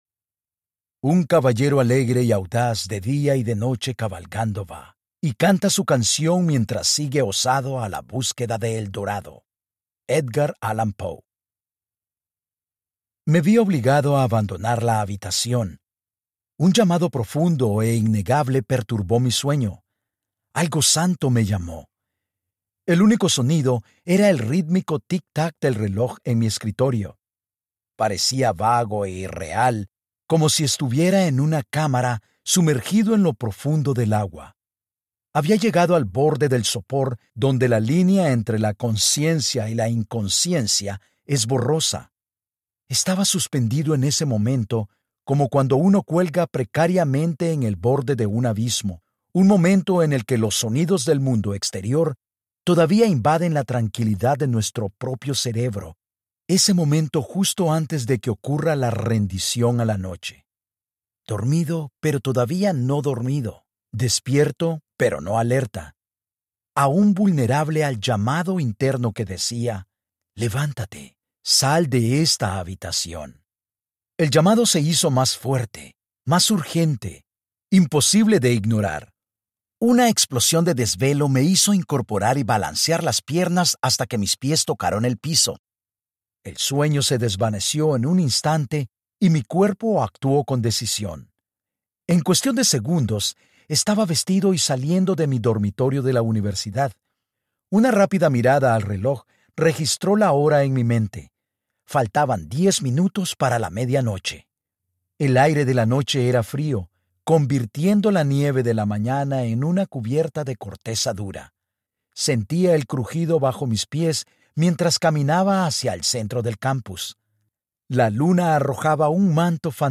The Holiness of God: R.C. Sproul - Audiobook Download (Spanish), Book | Ligonier Ministries Store